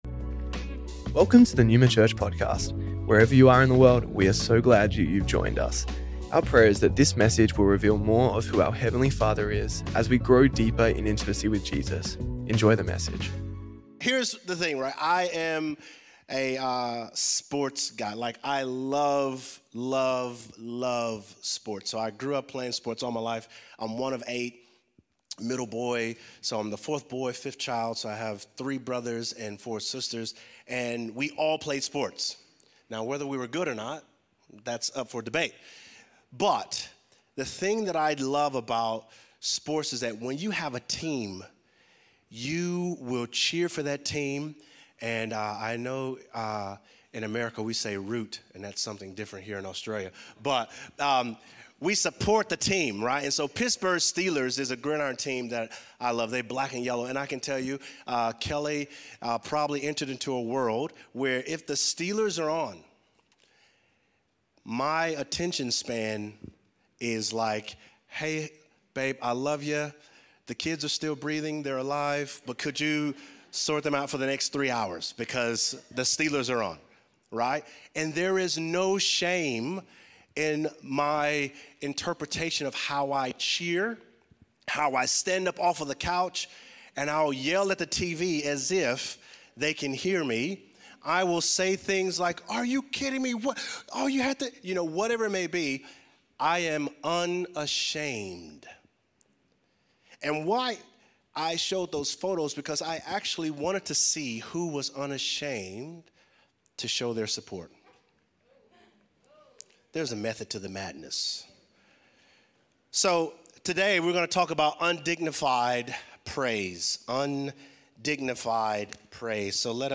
Neuma Church Melbourne South Originally recorded at the 10AM Service on Sunday 21st December 2025